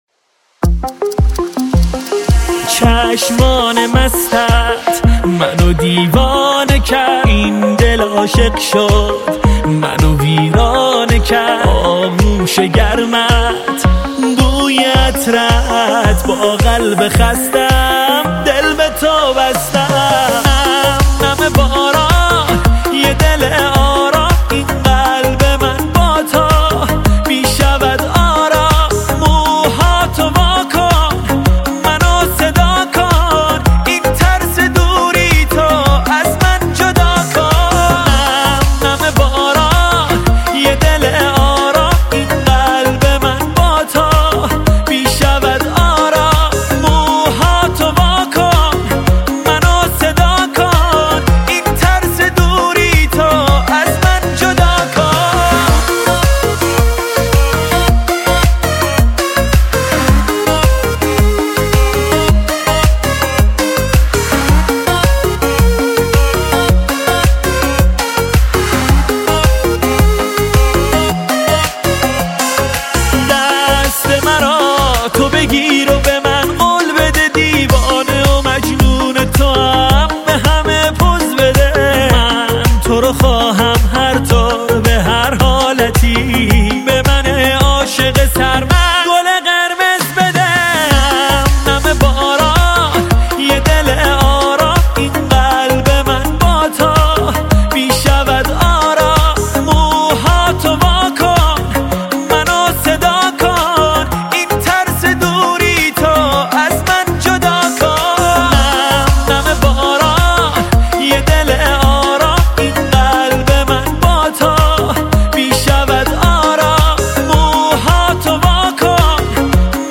با ریتم 6/8